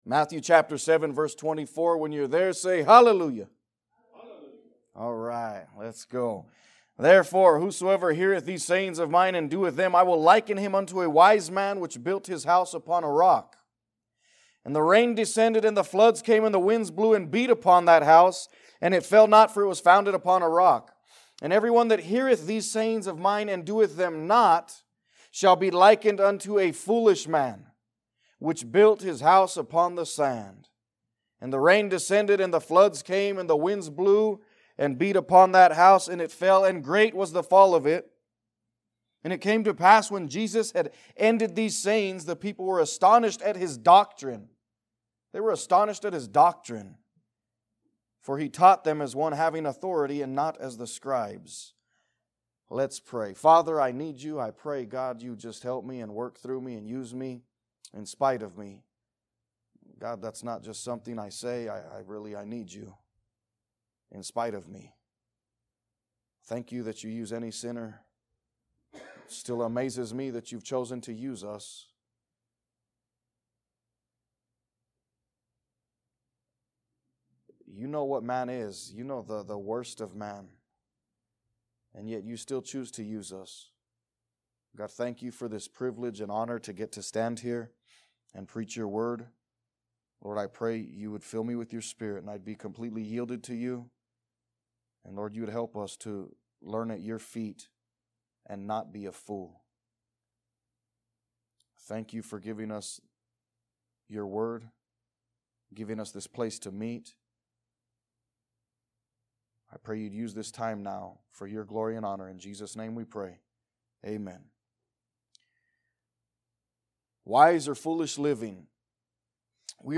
Sermons | Liberty Baptist Church